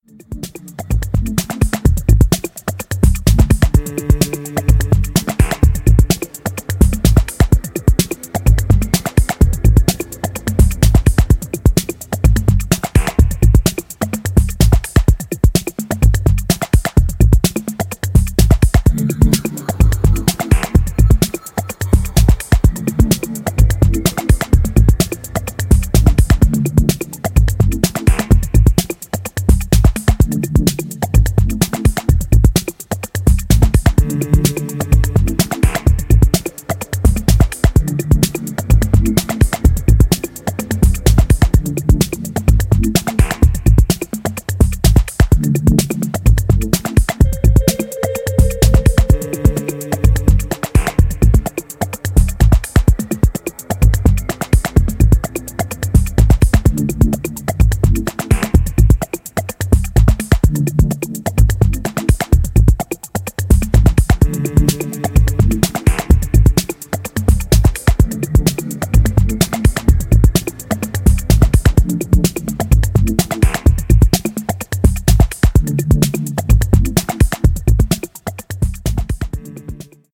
アナログ・ハードウェアを駆使して制作されたミニマル・ディープ・ハウスを披露しており、オブスキュアなパッドが揺蕩う